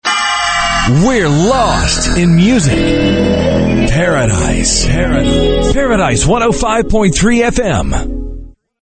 RADIO IMAGING / TOP 40